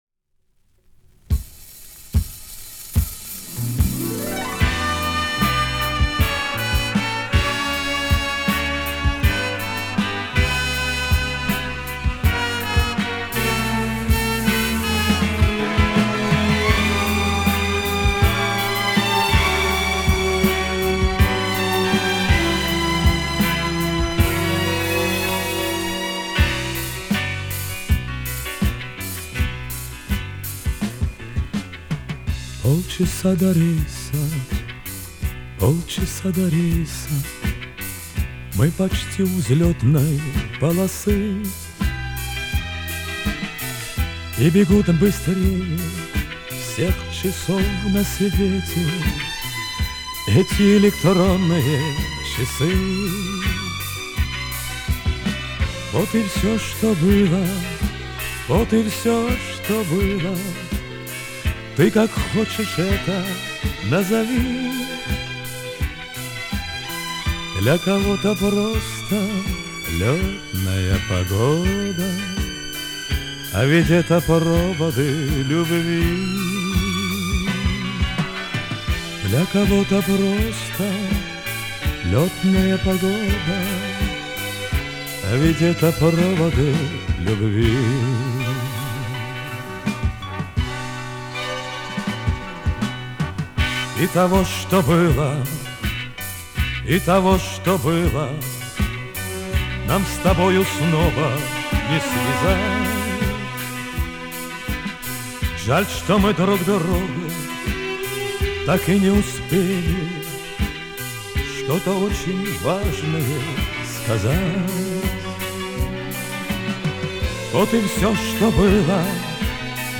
А это с миньона 1980г